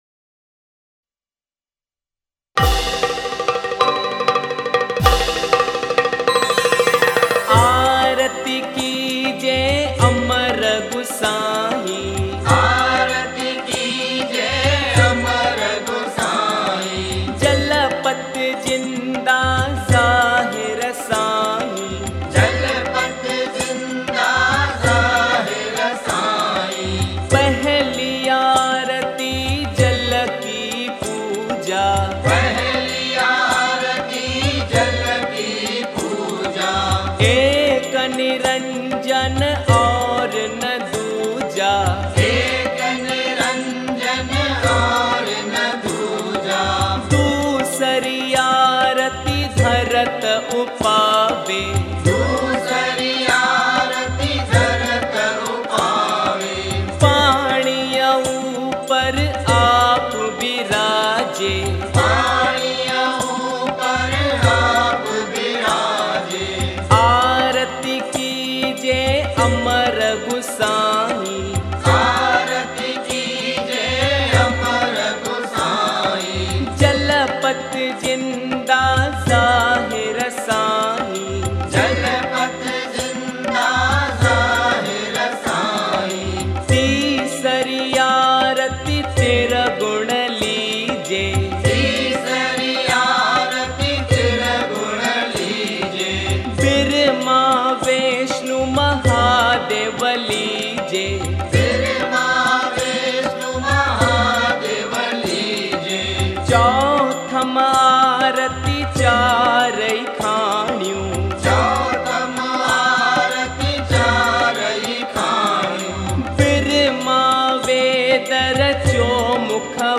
Sindhi Songs of Jhulelal